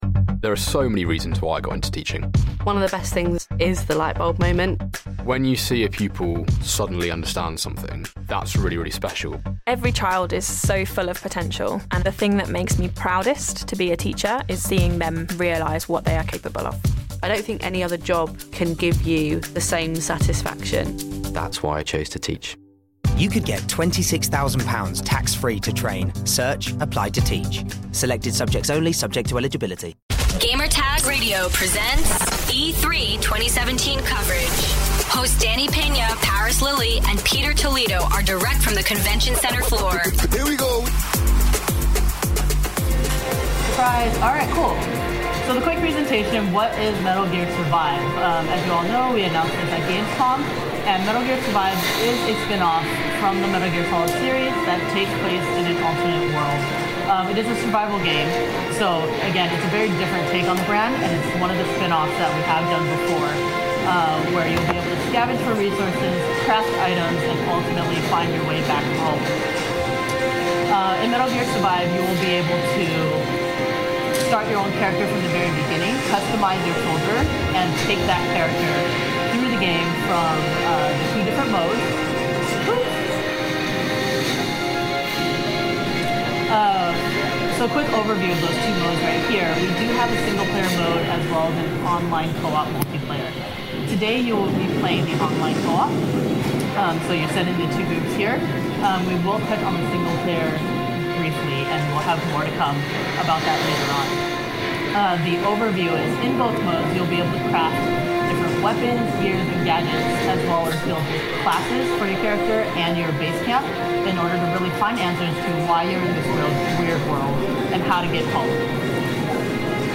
Presentation for Konami's Metal Gear Survive.